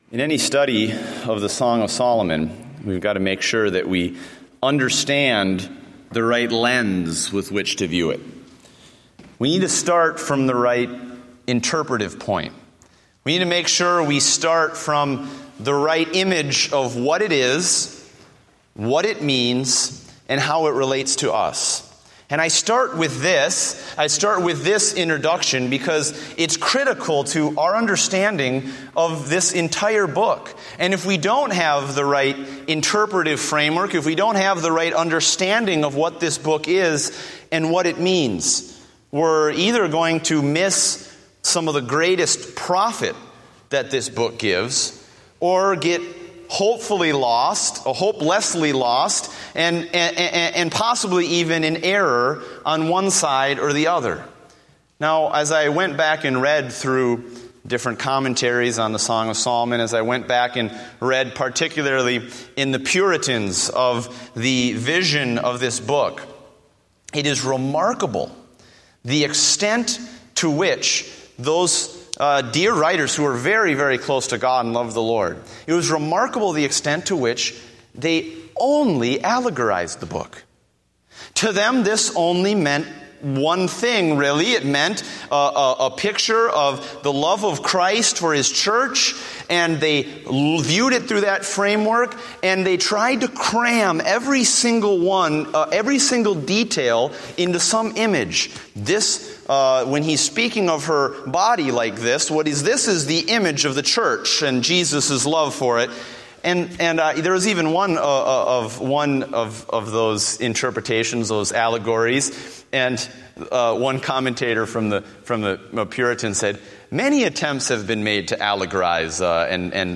Date: September 28, 2014 (Evening Service)